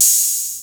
Southside Open Hatz (6).wav